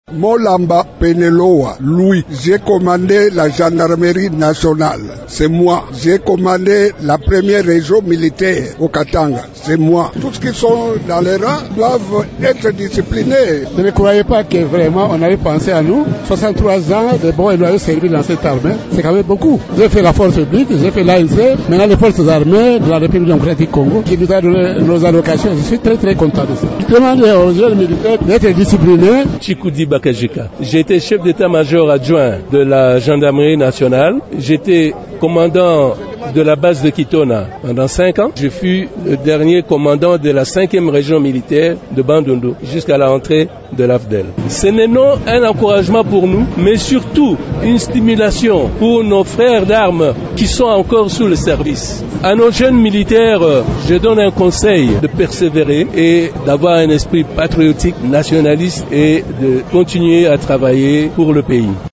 Vous pouvez suivre les témoignages des bénéficiaires des allocations de fin de leur fin de carrière en cliquant ici :